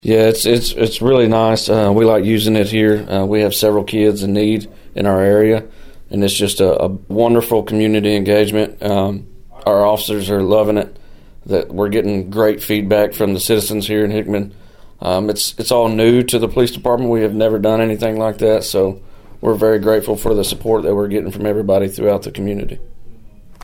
Hickman Police Chief Ryan Amberg said he and his officers were grateful for the community support to provide the shopping tour.